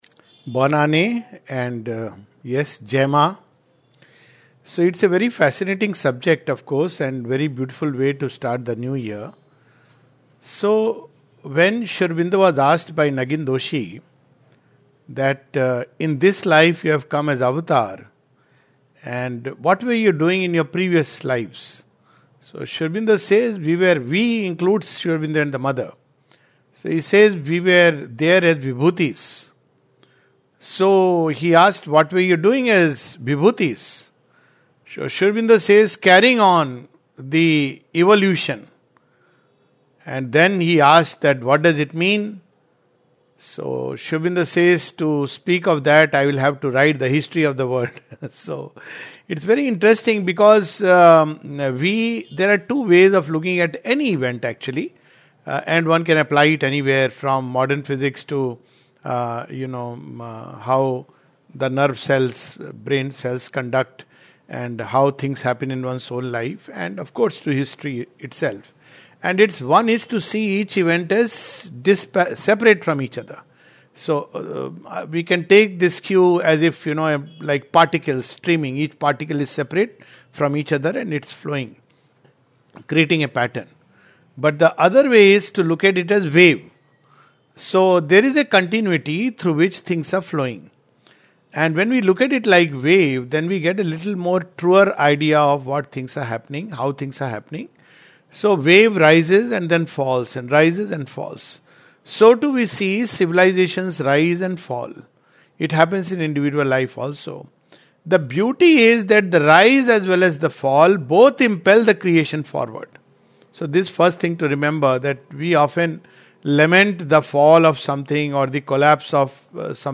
This is a webinar talk with Sri Aurobindo Yoga Foundation of North America.